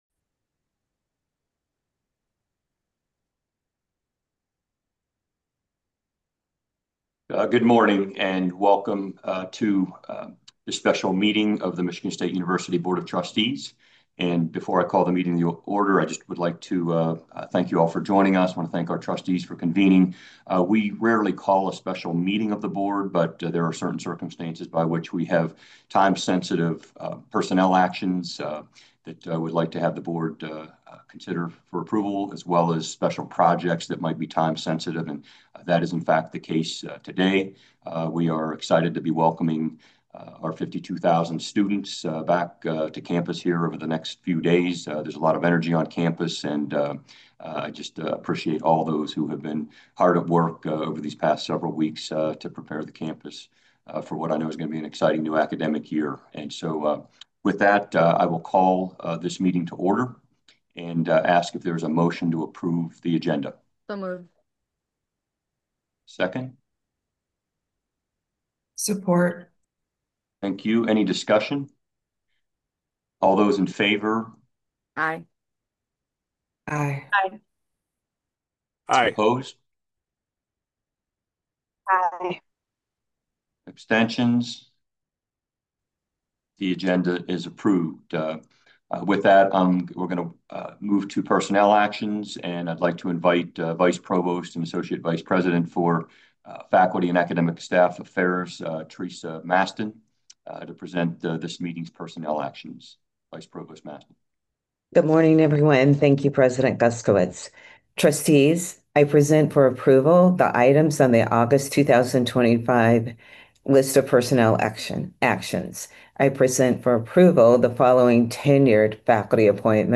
Home Meetings Past Meetings 2025 Meetings August 15, 2025 Meeting When: 8:00 a.m. Where: Zoom August 15, 2025 Audio Recording (8mb MP3 file) Agenda ( pdf ) Call to Order Approval of Proposed Agenda Personnel Actions February 13th Permanent Memorial Adjourn